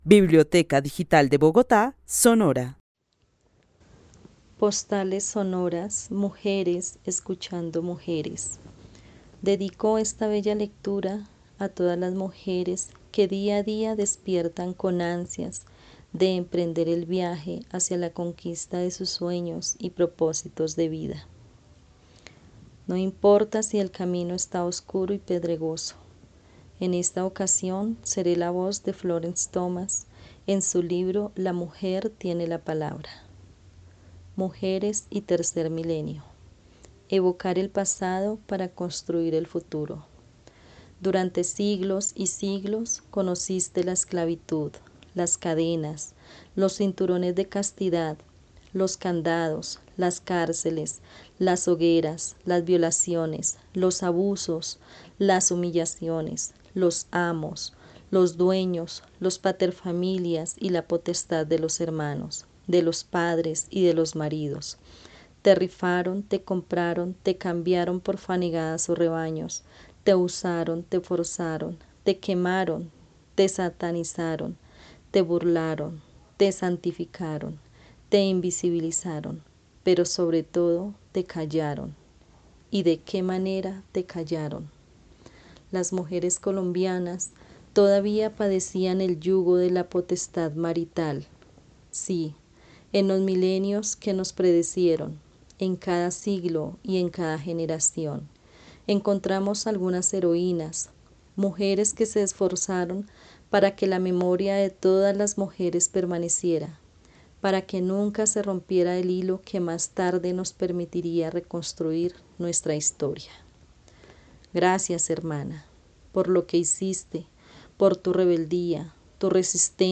Narración oral de una mujer que vive en la ciudad de Bogotá, dedica la lectura de un fragmento del libro "La mujer tiene la palabra" de Florence Thomas a todas las mujeres conquistadoras de sueños.
También admira a Florence Thomas, Mariana Pajón, Policarpa Salavarrieta, Totó la Momposina, Débora Arango y a una diversidad de mujeres que dejan y dejaron huella en la humanidad. El testimonio fue recolectado en el marco del laboratorio de co-creación "Postales sonoras: mujeres escuchando mujeres" de la línea Cultura Digital e Innovación de la Red Distrital de Bibliotecas Públicas de Bogotá - BibloRed.